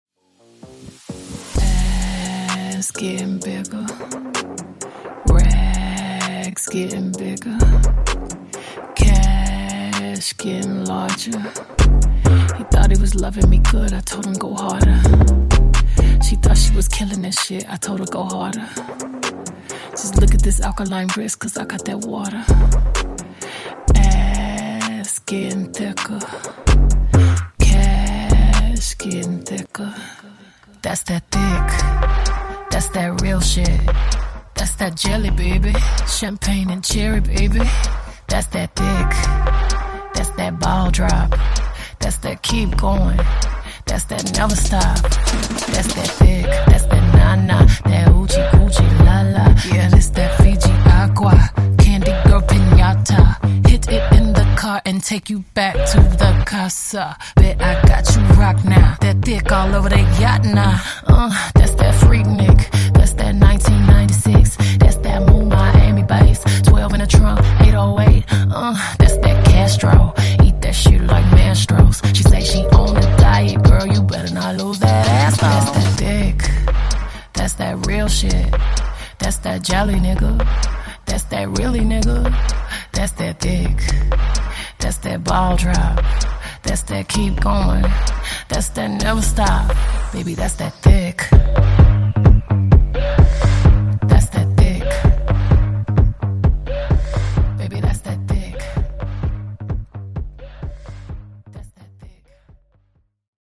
Genres: R & B , REGGAETON
Clean BPM: 120 Time